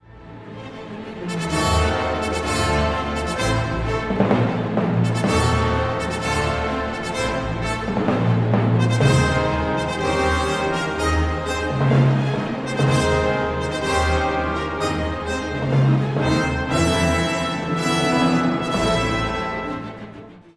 Franz Liszt – Los Preludios (1854) Deja un comentario Tonalidad inicial: La menor. Duración: 10 compases 4/4.